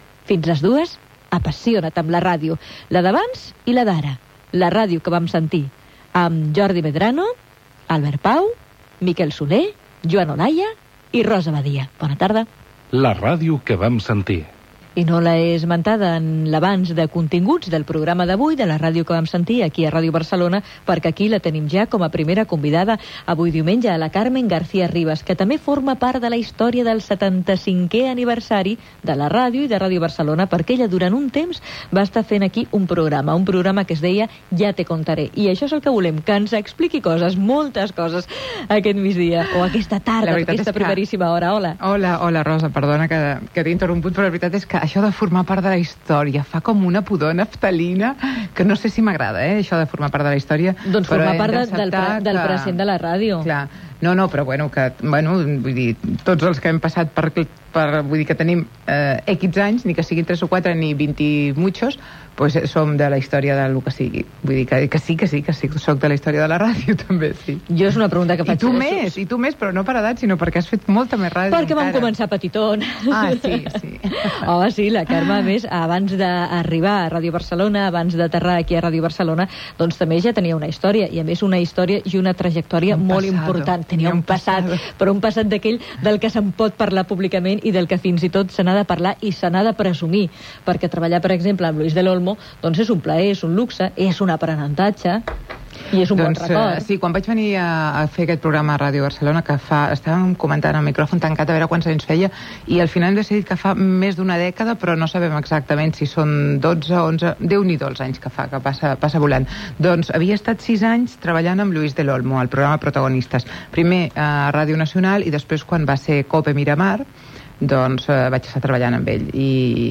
Entrevista
Divulgació